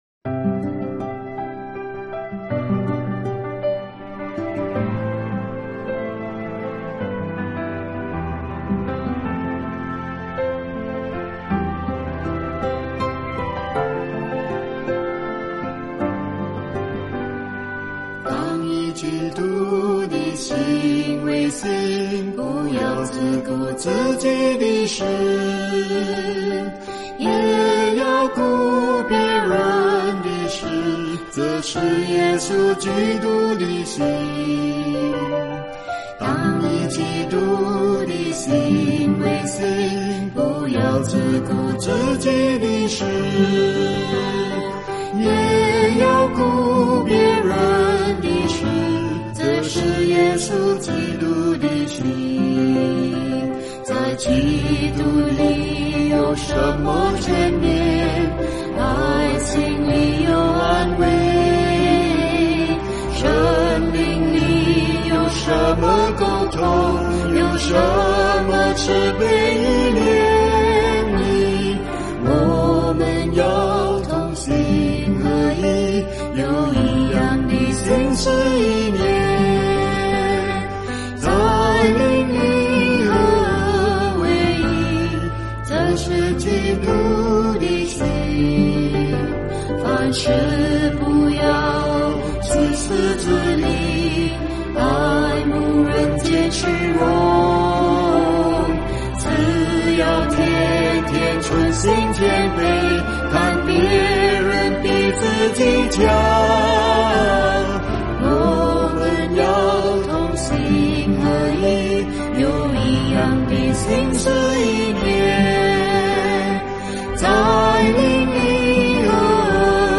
赞美诗 | 基督的心